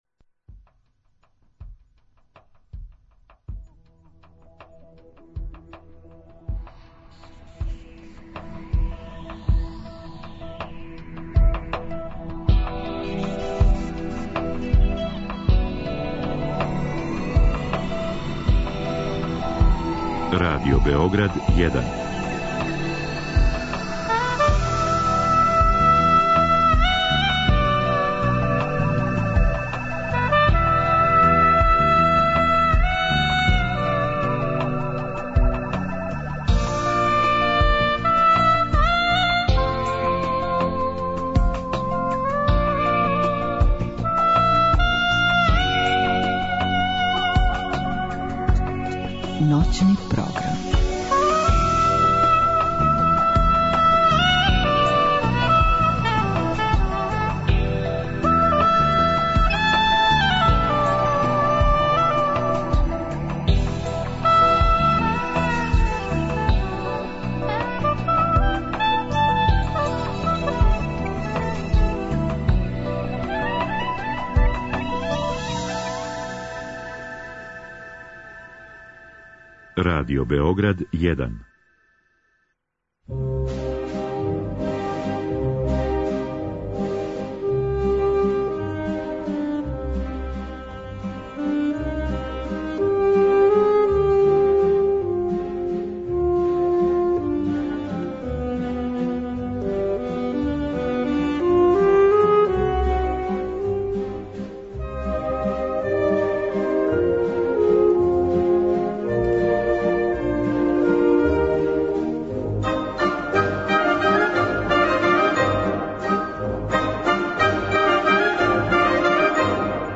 Разговараћемо о 49. фестивалу Мокрањчеви дани, који је у току, а емисију реализујемо уживо из Неготина, родног града Стевана Мокрањца, од чије се смрти ове године обележава 100 година.